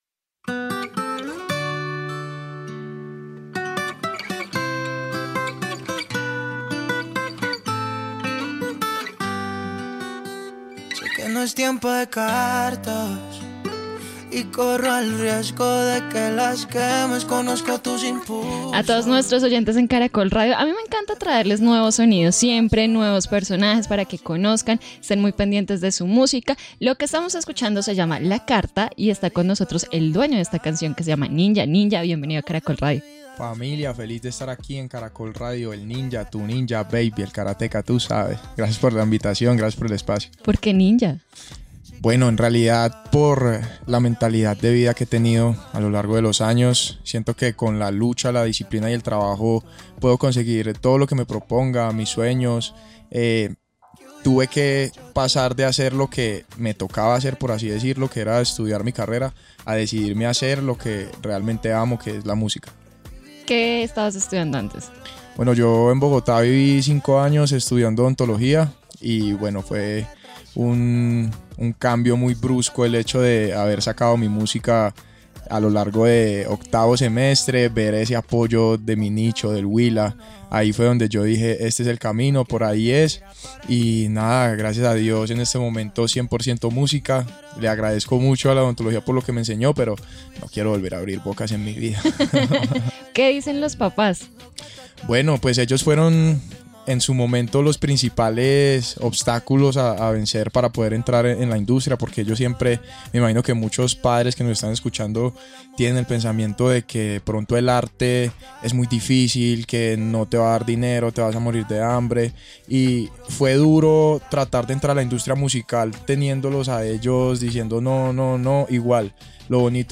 En entrevista con Caracol Radio